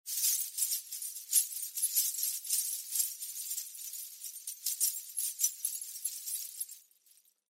Danza árabe, bailarina haciendo el movimiento shimmi 04
continuo
moneda
Sonidos: Acciones humanas